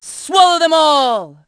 Theo-Vox_Skill4.wav